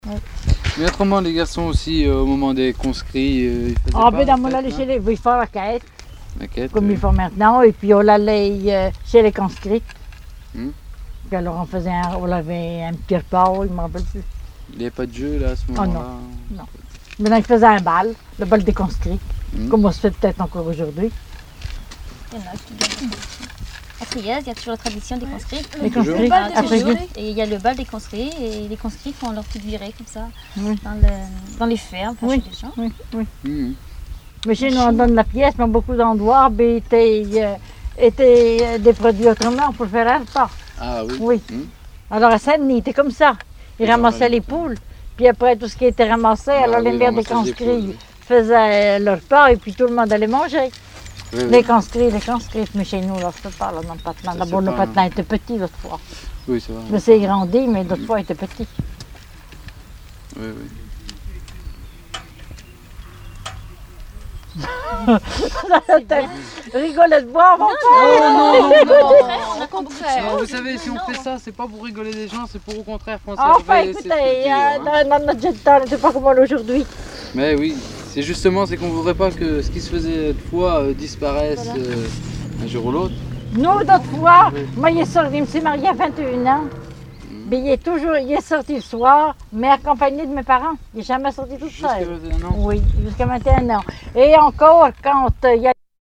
chanteur(s), chant, chanson, chansonnette
Catégorie Témoignage